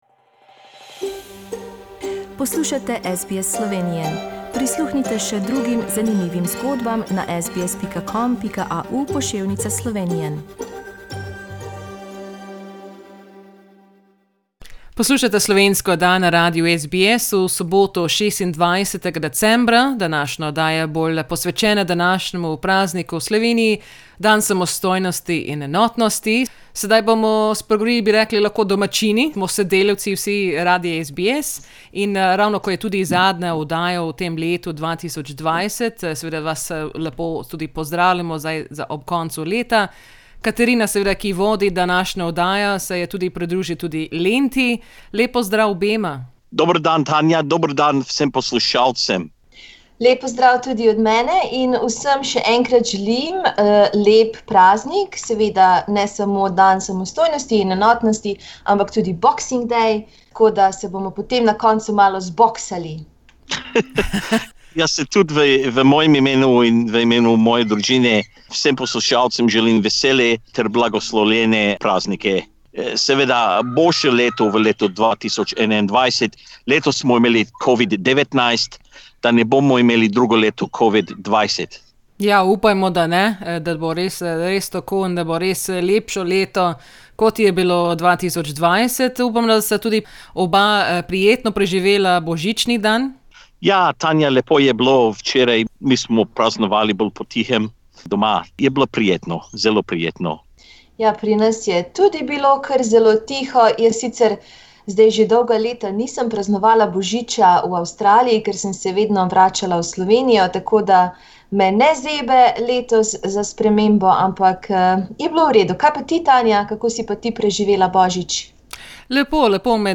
V zadnji oddaji leta smo se oglasili vsi sodelavci slovenske oddaje.